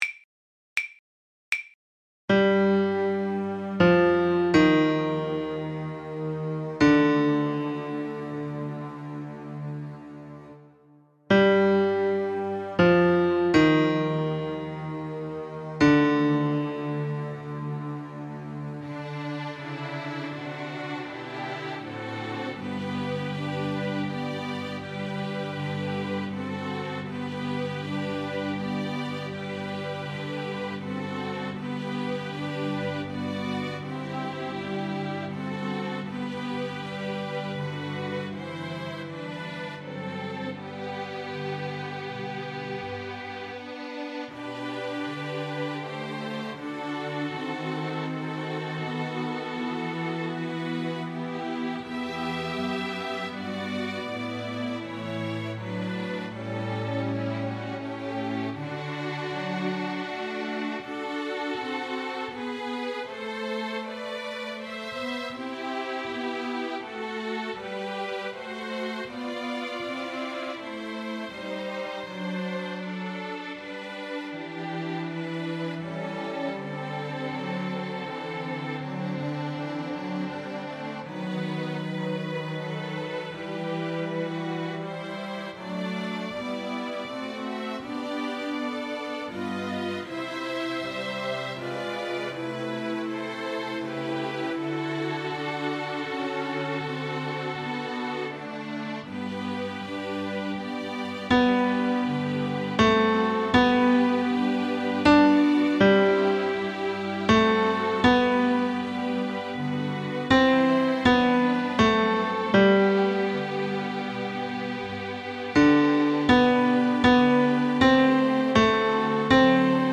Tenor
Mp3 Música